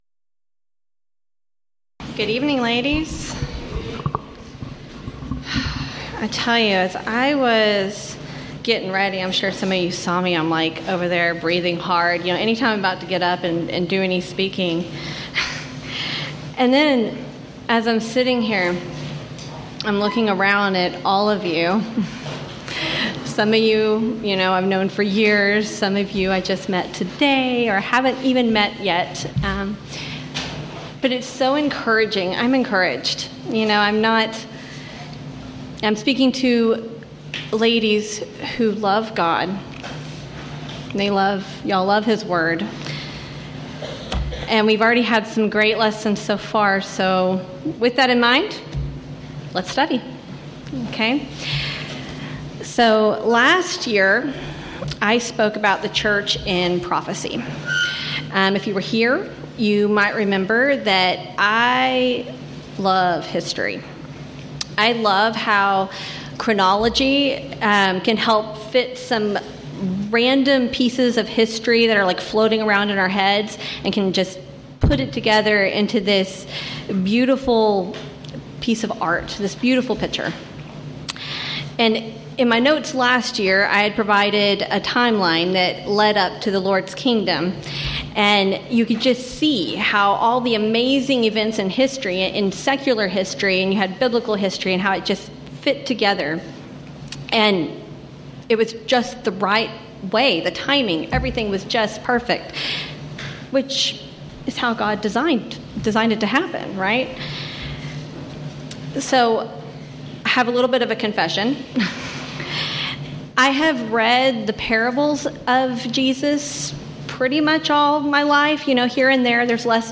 Event: 9th Annual Texas Ladies in Christ Retreat
Ladies Sessions